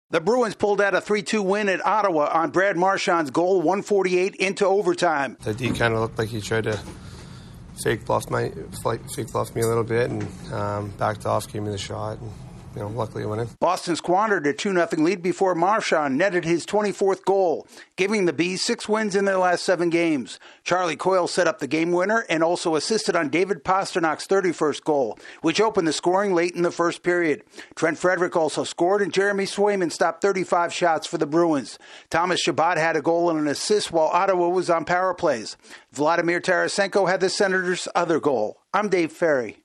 The Bruins beat the Senators in extra time. AP correspondent